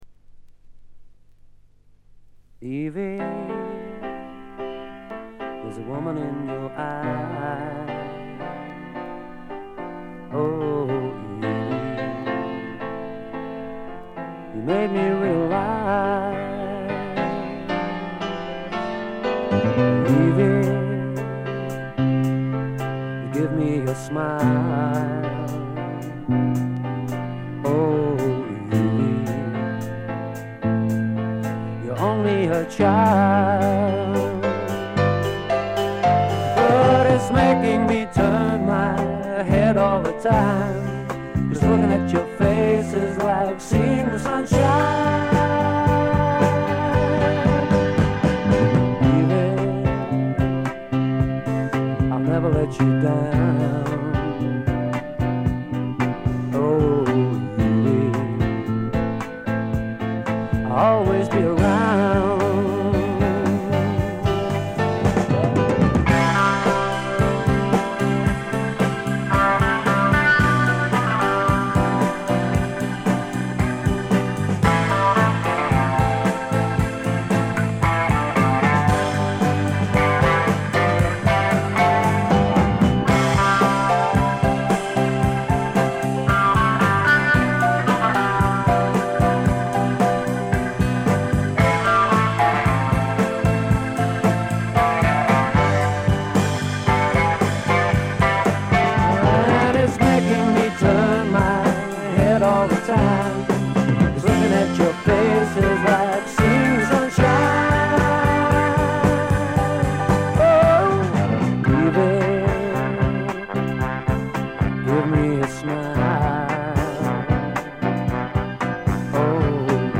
静音部での微細なバックグラウンドノイズ程度。
マッスルショールズ録音の英国スワンプ大名盤です！
試聴曲は現品からの取り込み音源です。